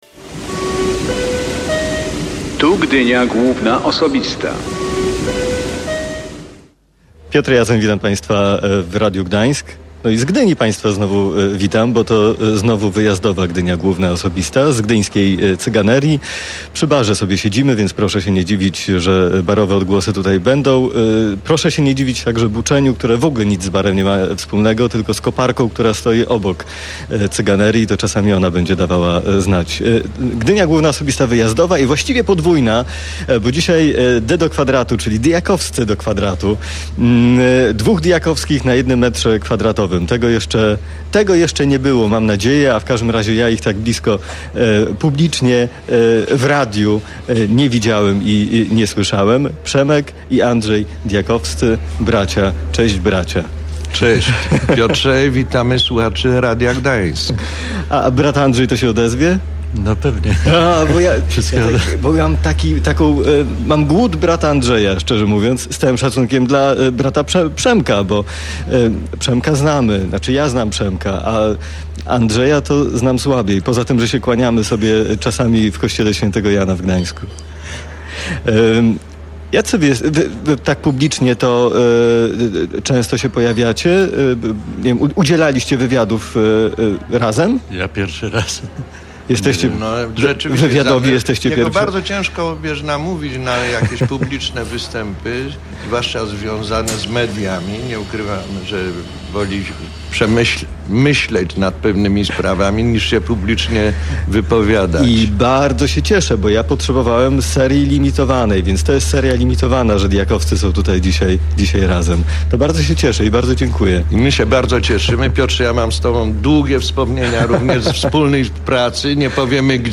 Piotr Jacoń rozmawiał w gdyńskiej kawiarni Cyganeria z artystami o ich młodości i o tym, jak to się stało, że dwaj bracia z Małopolski zamieszkali nad morzem.